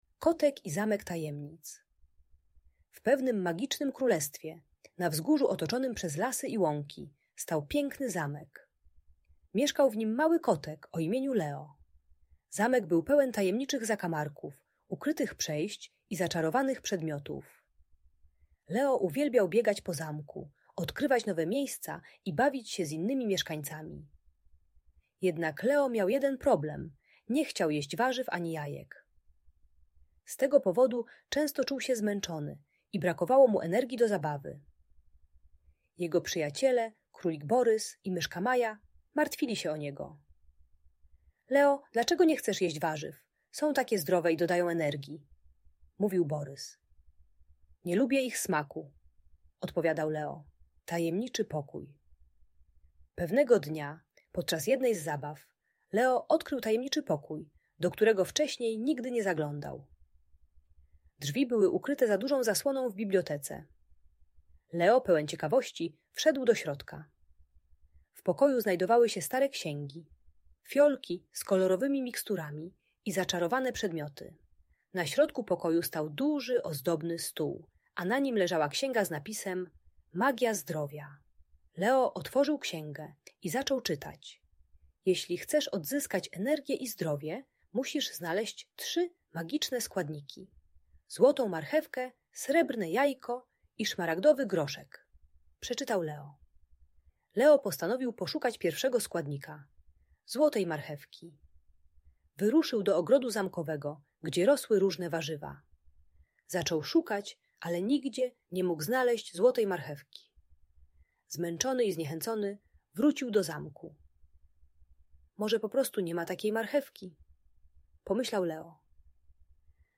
Przygody Kota Leo - Magiczna Opowieść dla Dzieci - Audiobajka